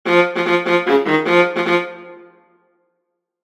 A descending figure (a), (two thirds at the interval of a seventh) characterizes the brooding thoughtfulness, the cogitation of Mime; the fact that the dwarf is a Niblung Wagner publishes by means of a rhythmical phrase like the pounding of hammers (b):